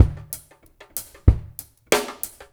ABO DRUMS1-L.wav